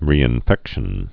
(rēĭn-fĕkshən)